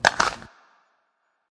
枪支重装的环境1
描述：简单重装M9。立体声和相当宽敞的声音。用2个轱辘电容式麦克风录制的。
Tag: 环绕 是bient FX 手枪 重装 幻灯片 声音 9毫米 武器